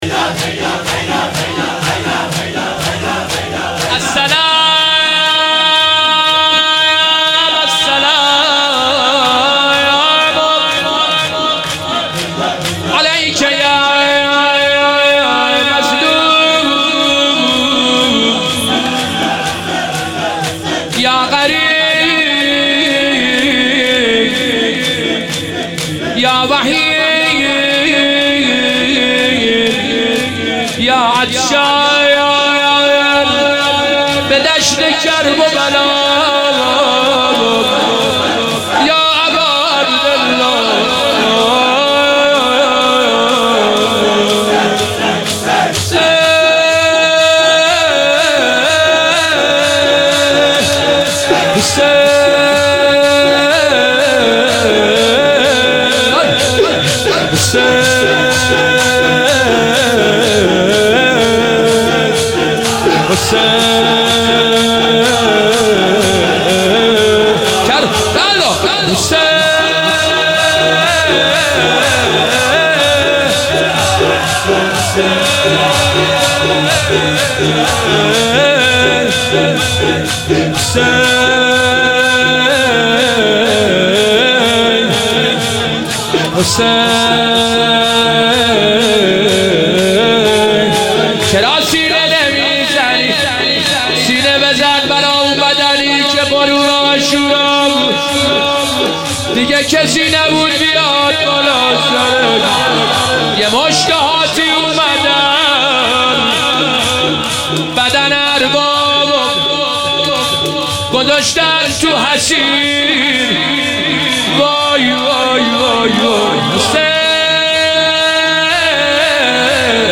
مناسبت : شب بیست و سوم رمضان - شب قدر سوم
مداح : حسین سیب سرخی قالب : شور